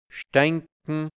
Mundart-Wörter | Mundart-Lexikon | deutsch-hianzisch | Redewendungen | Dialekt | Burgenland | Mundart-Suche: D Seite: 9